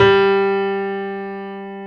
G2-PNO93L -L.wav